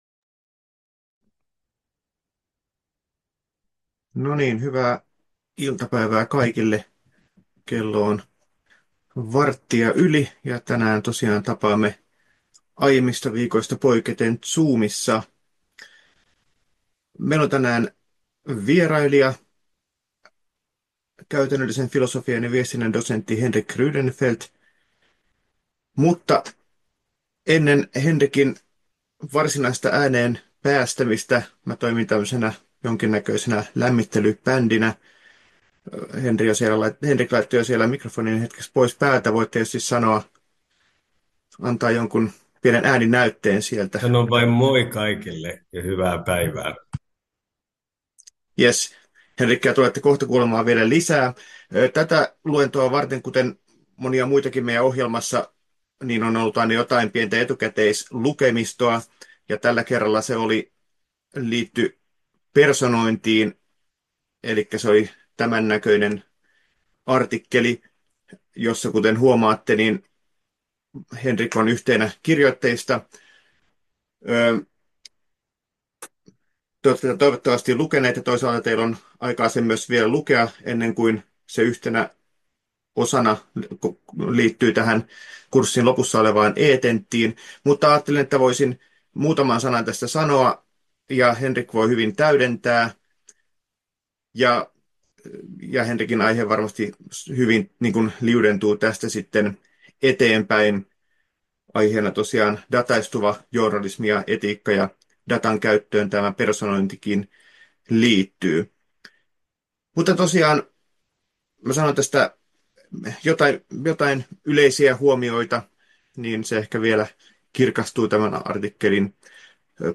Luennon 17.2. tallenne